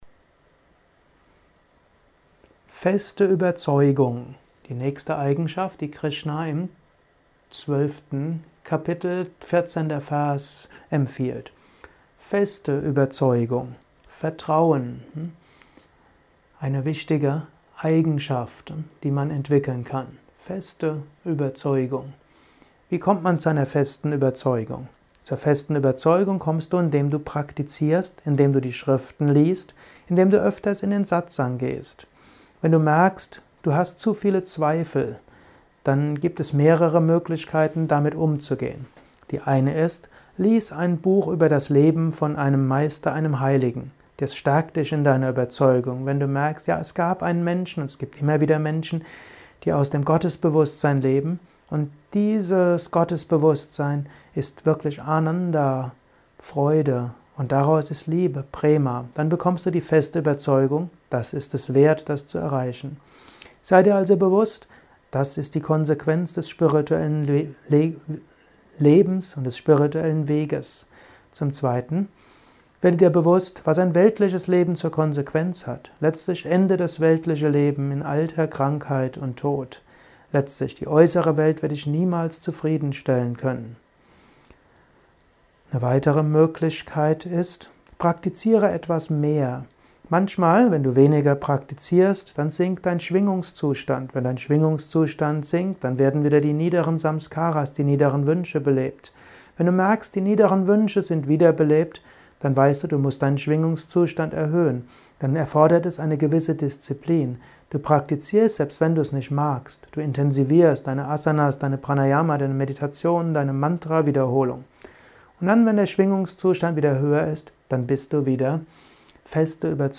Kurzvorträge